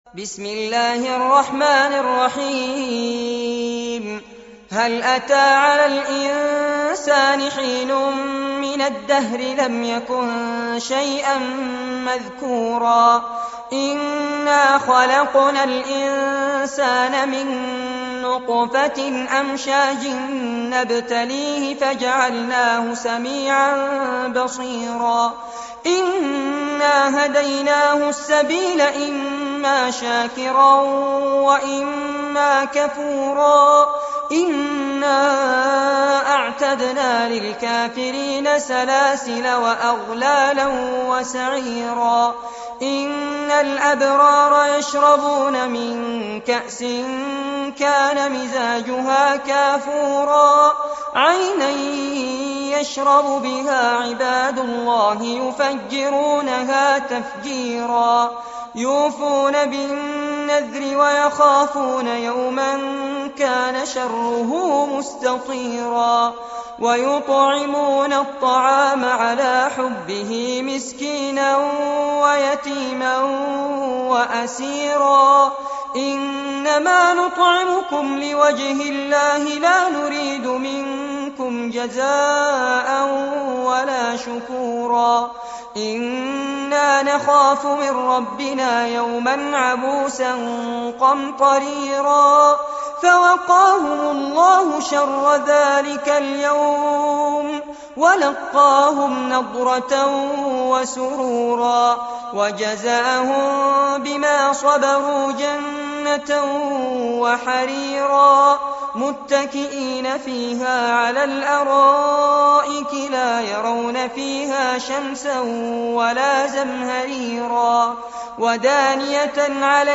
سورة الإنسان- المصحف المرتل كاملاً لفضيلة الشيخ فارس عباد جودة عالية - قسم أغســـــل قلــــبك 2
القرآن الكريم وعلومه     التجويد و أحكام التلاوة وشروح المتون